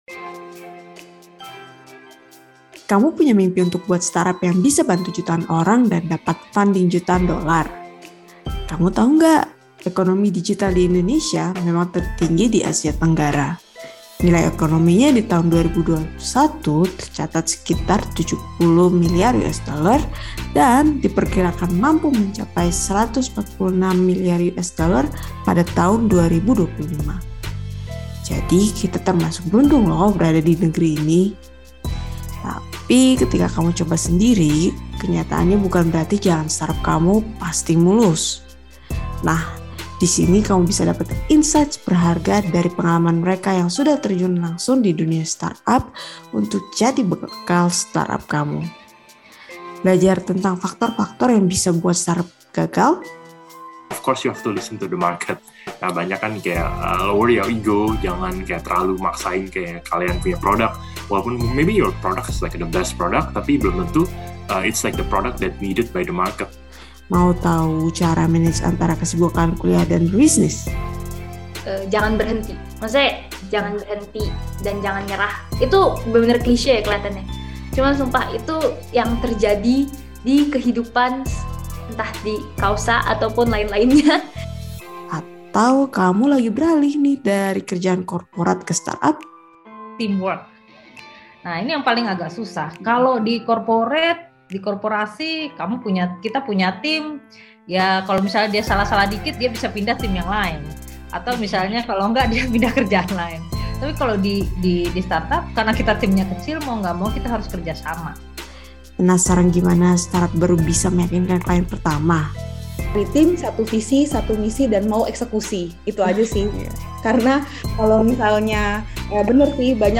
Bisa – Bisa (Bincang-Bincang Startup bareng BINUS Startup Accelerator) Podcast is a podcast where startup founders, especially those that have just started, can listen to experiences and insights shared by industry players. The content is presented in casual, yet insightful conversations.